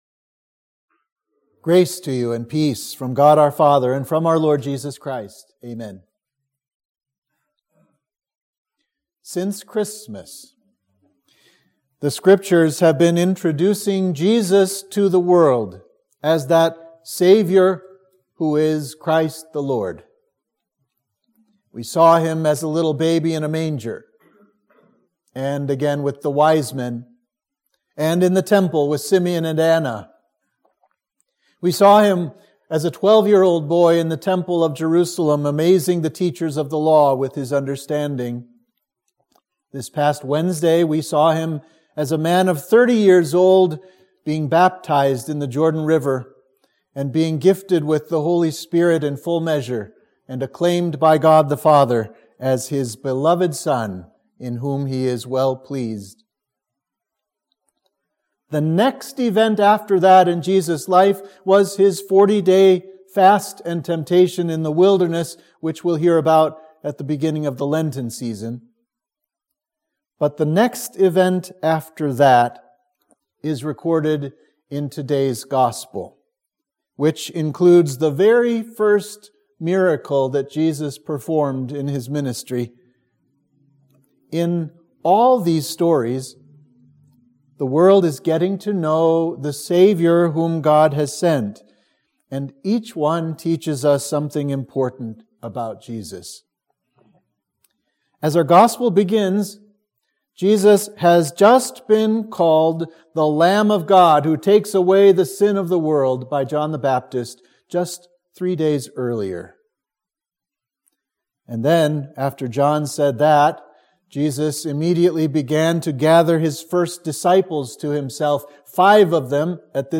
Sermon for Epiphany 2